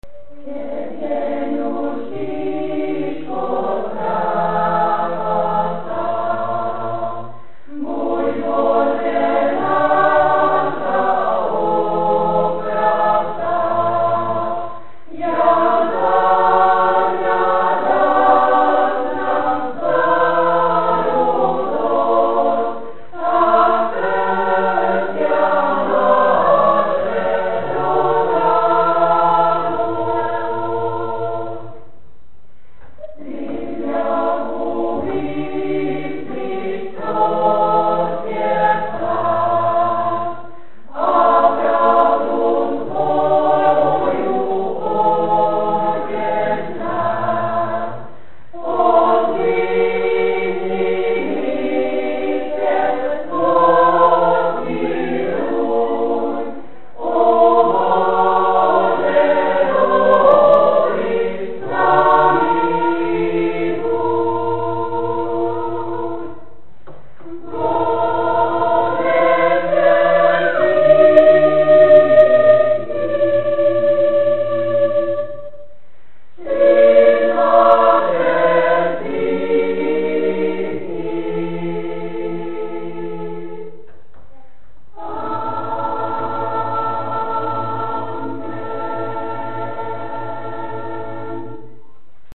Énekkar